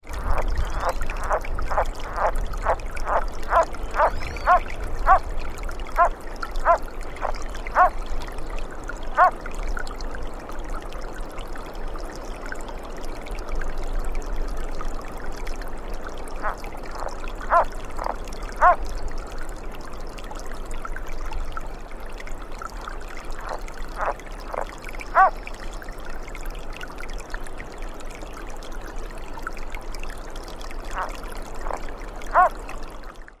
It is a short and rasping call often accelerated and rising at the end, sometimes preceded by calls that don't rise at the end.
Water flowing from snow-melt into the small lake can be heard in the background, along with occasional singing birds.
Sound This is 34 seconds of the calls of the frog heard above recorded in the air.